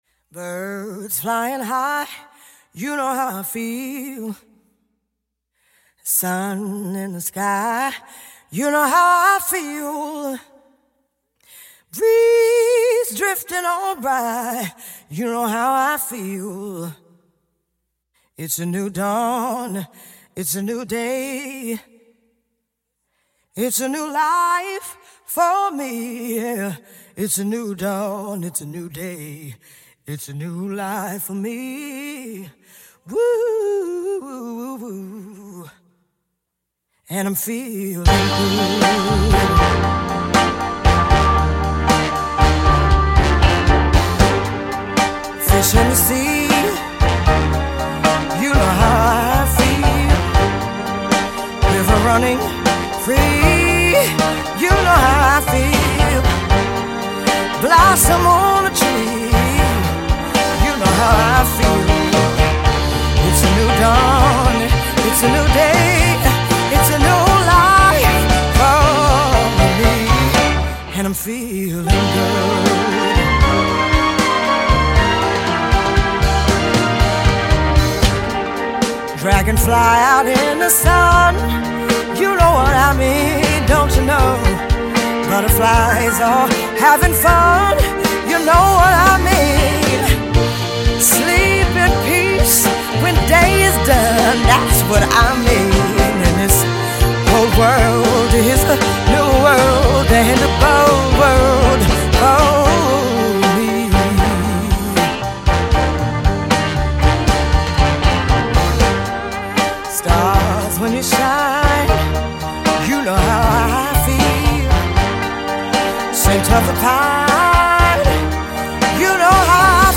smoky vocals
singing acapella, to the traditional jazz version
electric jazz vocal as the track progresses.